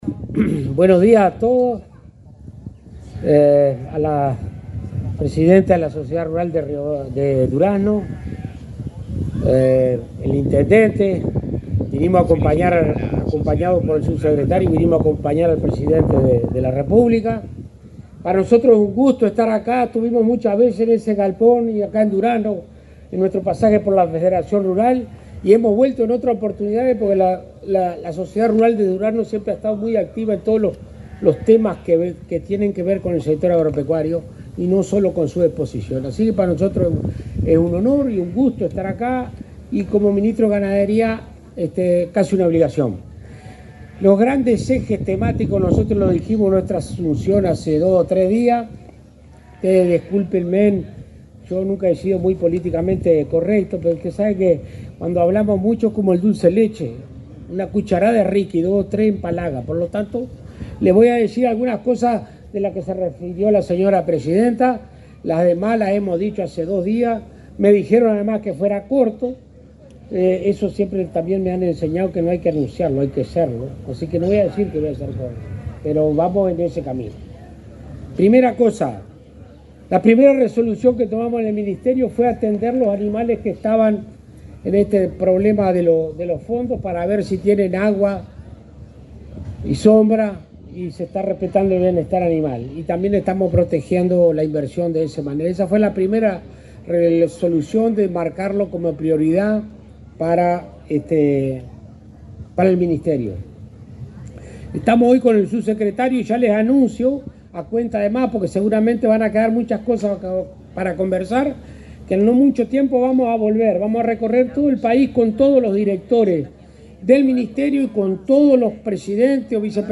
Palabras del ministro de Ganadería, Alfredo Fratti
El ministro de Ganadería, Agricultura y Pesca, Alfredo Fratti, recordó las prioridades de su gestión, durante el acto de clausura de la Expo Durazno.